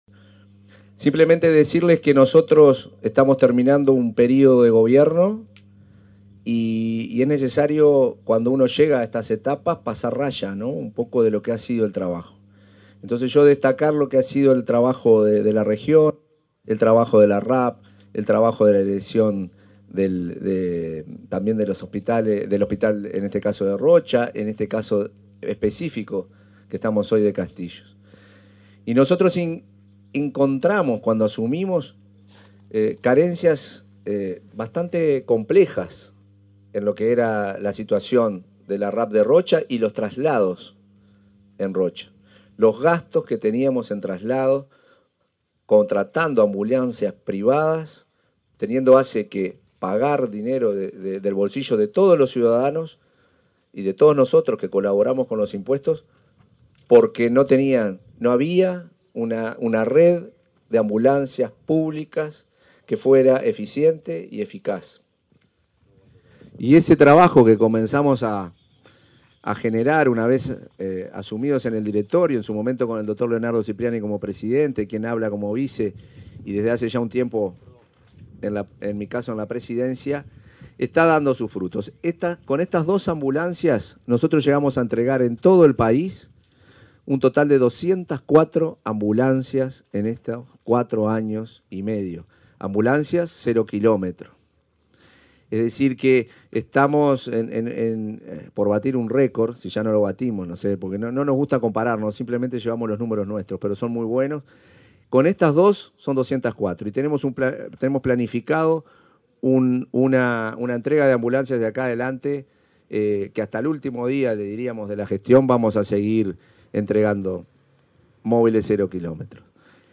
Palabras del presidente de ASSE, Marcelo Sosa
Palabras del presidente de ASSE, Marcelo Sosa 27/09/2024 Compartir Facebook X Copiar enlace WhatsApp LinkedIn Este viernes 27, el presidente de la Administración de los Servicios de Salud del Estado (ASSE), Marcelo Sosa, participó en el acto de entrega de dos ambulancias especializadas para el departamento de Rocha, que serán destinadas al centro auxiliar de Castillos y la policlínica de La Coronilla.